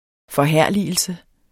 Udtale [ fʌˈhæɐ̯ˀliəlsə ]